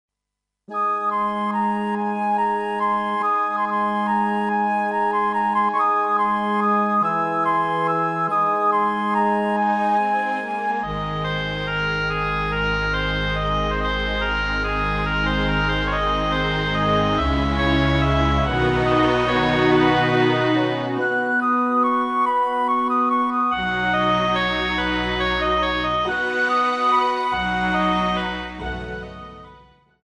平日に流れるチャイム